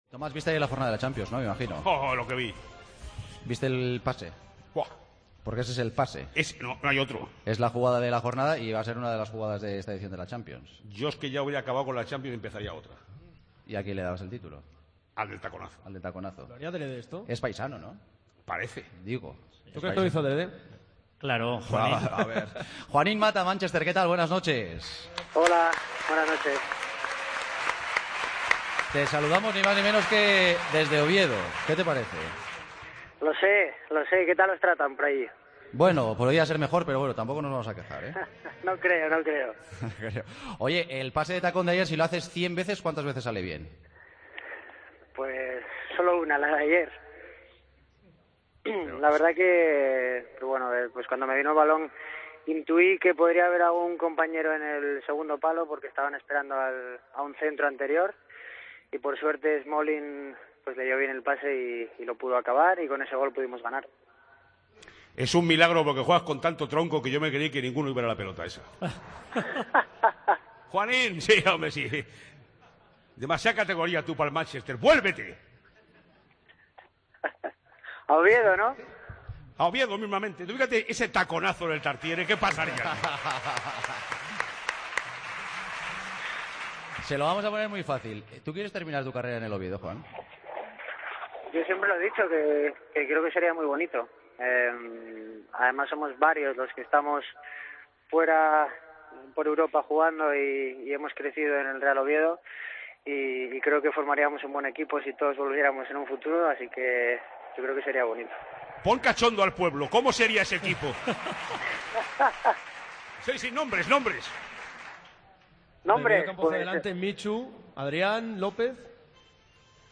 El jugador del Manchester United, en el programa especial desde Oviedo, el comienzo de temporada del conjunto inglés y su gran asistencia de tacón en el partido de Champions ante el Wolfsburgo.